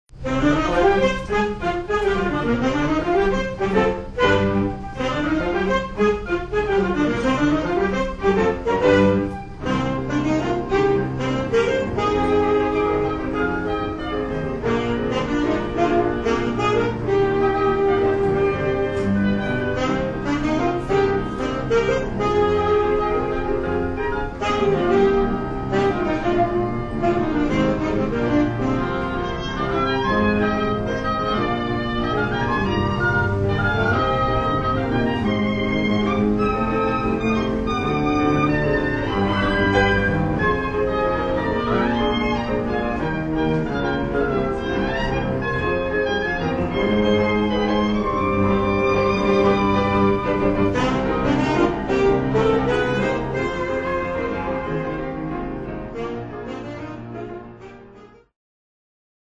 Flute、Oboe、Clarinet、Sax、Cello、Piano
（244,478 bytes） 太陽のように激しく、月のようにしなやかな情熱。